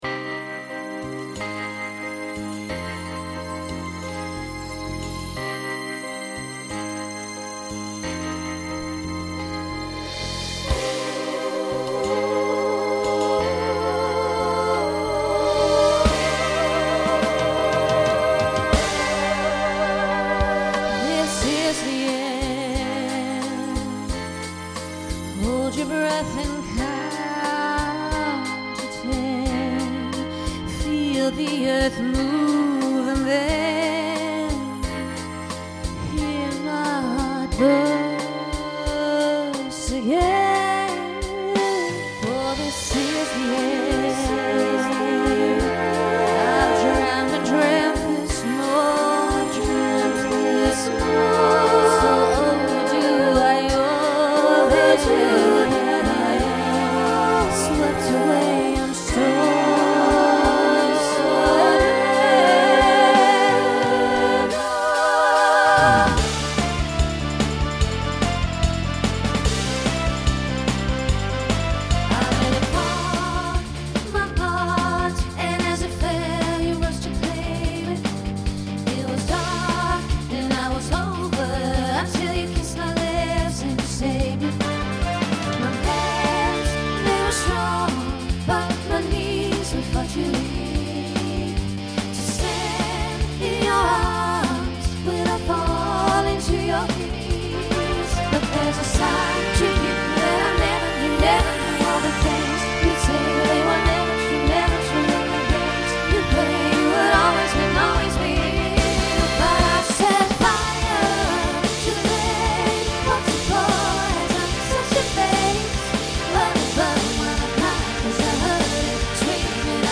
Voicing SSA Instrumental combo Genre Pop/Dance , Rock